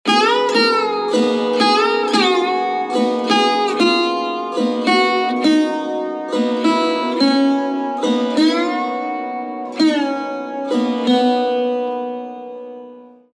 Raga
AvarohaS’ D N P D M P G m R, P, R S
Gaud Sarang (Avaroha)